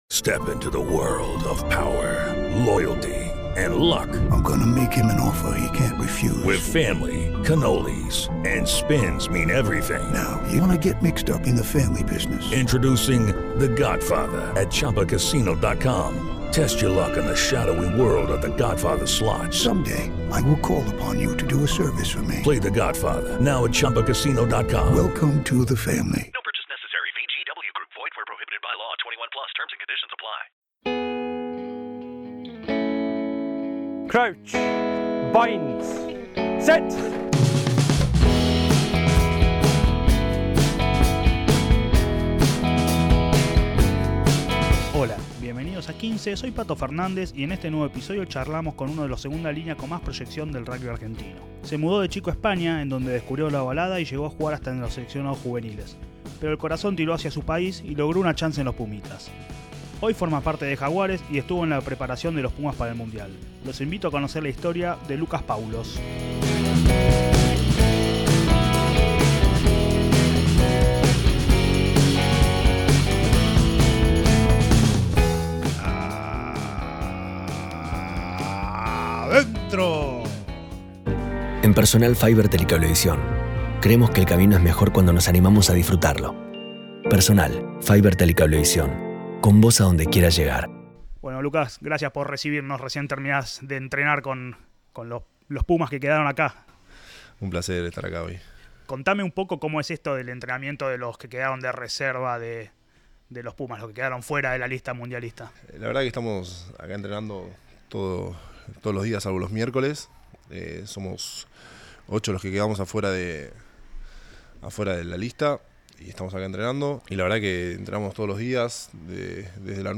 Charlas de rugby con los protagonistas!